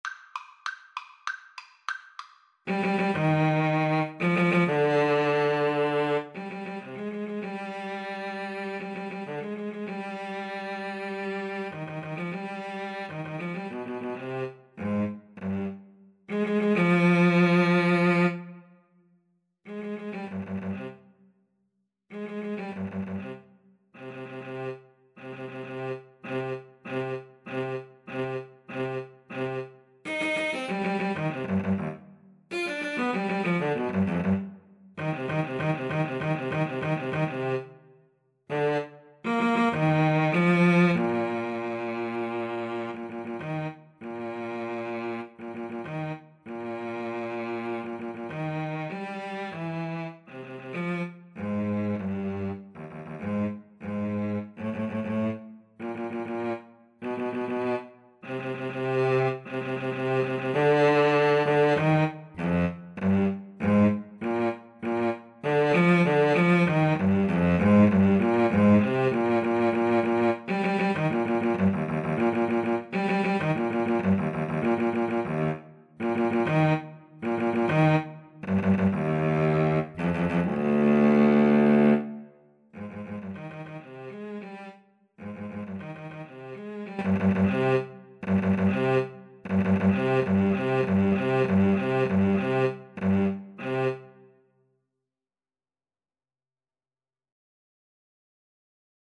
2/4 (View more 2/4 Music)
Allegro con brio (=108) =98 (View more music marked Allegro)
Classical (View more Classical Violin-Cello Duet Music)